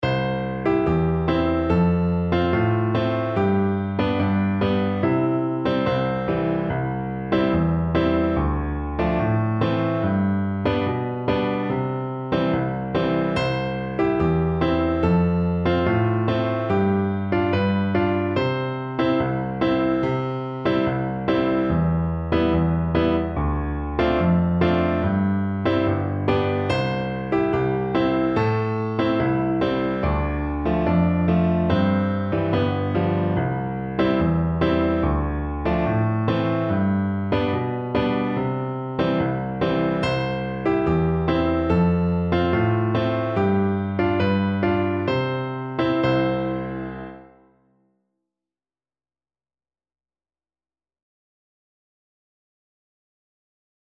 Flute
C major (Sounding Pitch) (View more C major Music for Flute )
Joyfully
2/4 (View more 2/4 Music)
Traditional (View more Traditional Flute Music)